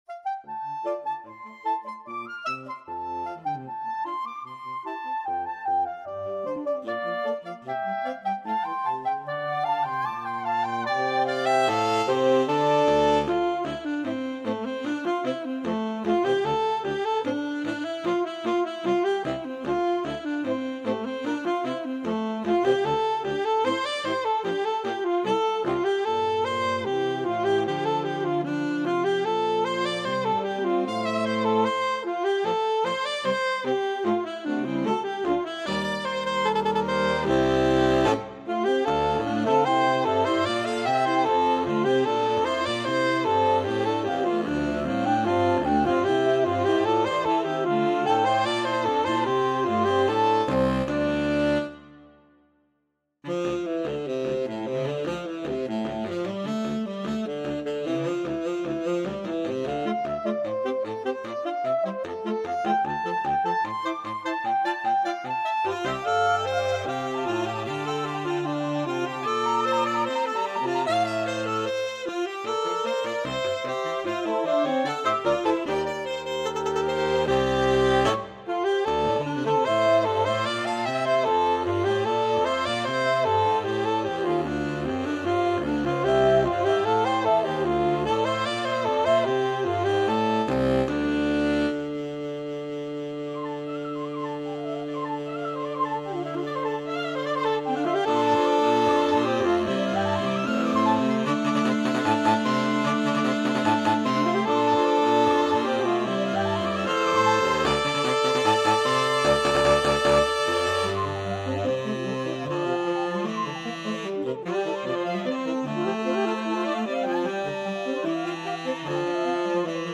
for saxophone quartet
Four voices moving to three very different rhythmic lines.